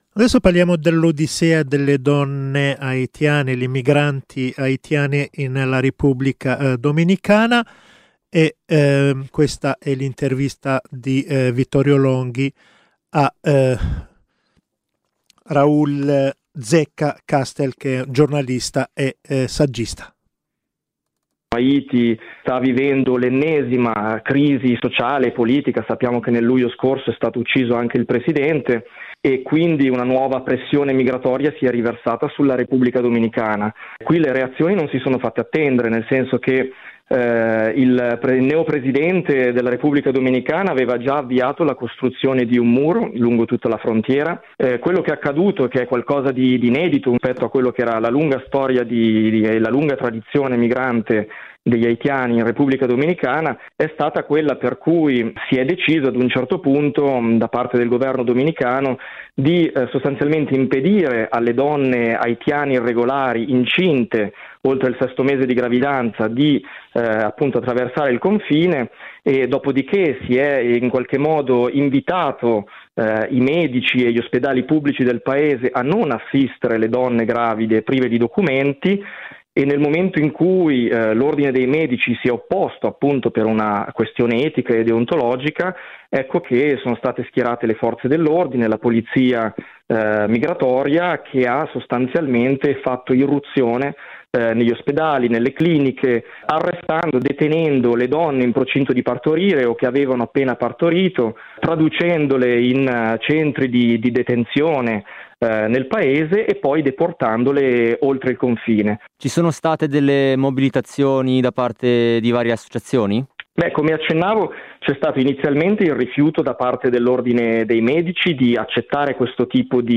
Qui di seguito l’intervista registrata e trasmessa da RadioPopolare di Milano.